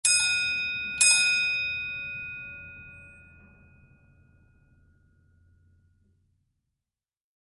Office Bell 2 Sound Effect Free Download
Office Bell 2